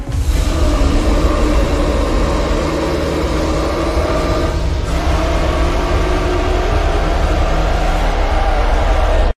KaijuNo.!Roar.ogg